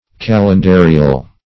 Kalendarial \Kal`en*da"ri*al\, a.
kalendarial.mp3